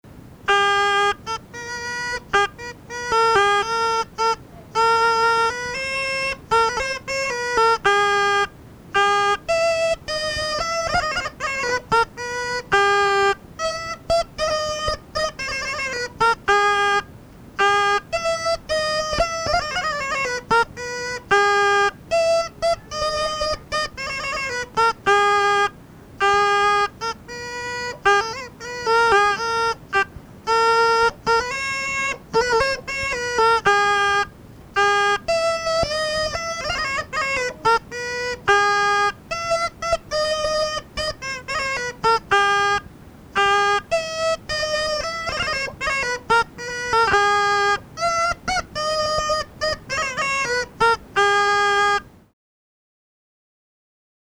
Голоса уходящего века (Курское село Илёк) Расцветали яблони и груши (рожок, инструментальная версия)